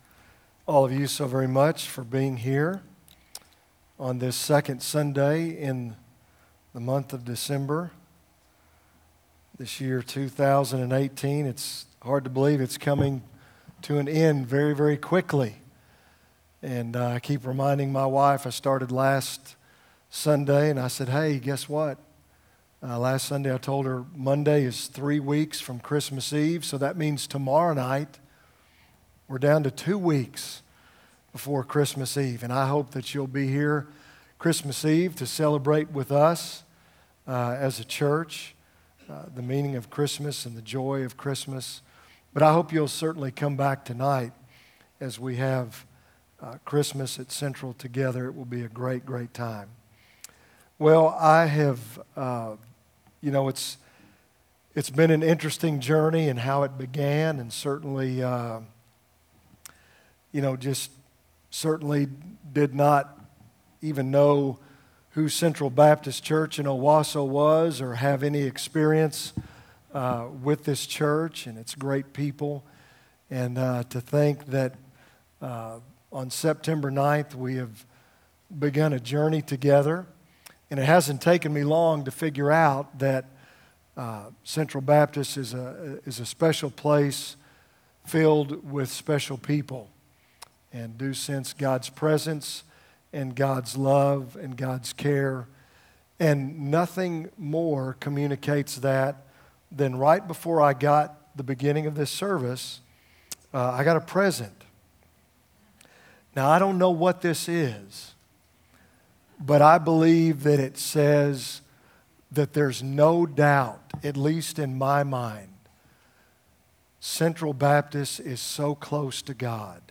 A message from the series "For Unto Us." Our final week of our 2018 Christmas series - For Unto Us